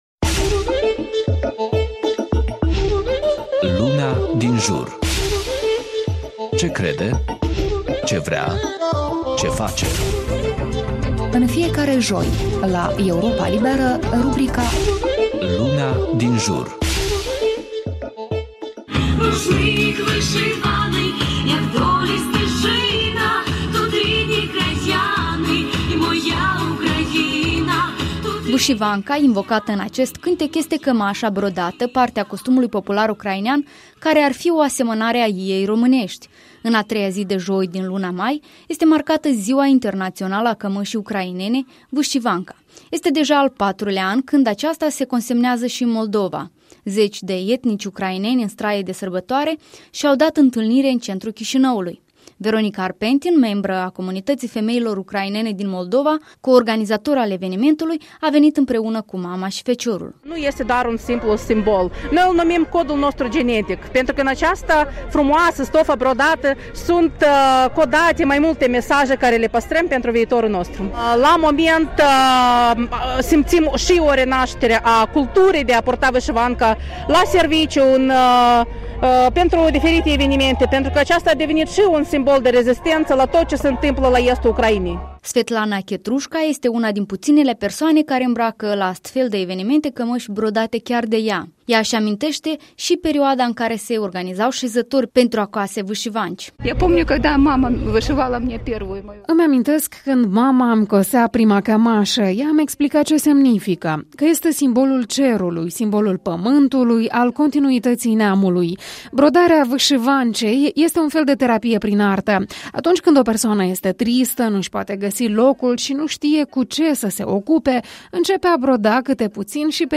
Vîșivanka invocată în acest cântec este cămașa brodată, parte a costumului popular ucrainean care ar fi o asemănare a iei românești. În a treia zi de joi din luna mai este marcată Ziua Internațională a Cămășii Ucrainene Vîșivanka.
Zeci de etnici ucraineni în straie de sărbătoare și-au dat întâlnire în centrul Chișinăului.